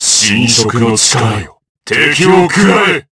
DarkKasel-Vox_Skill5_jp_b.wav